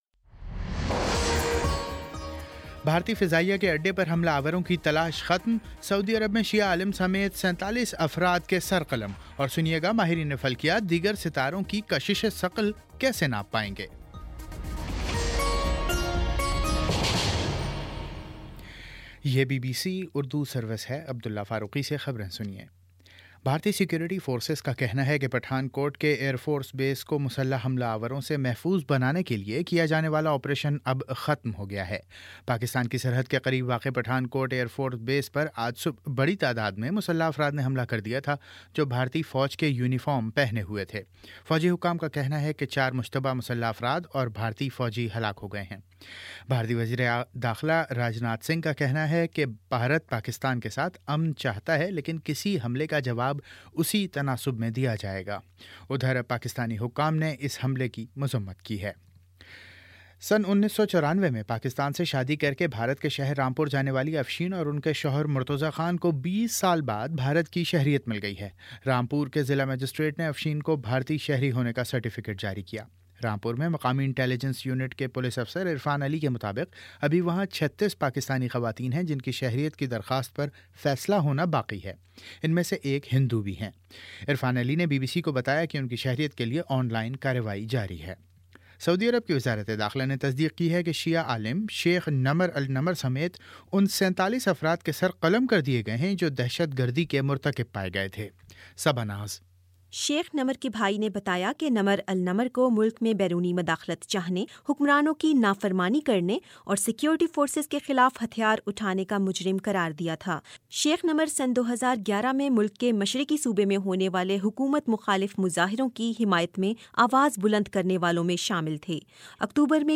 جنوری 02 : شام چھ بجے کا نیوز بُلیٹن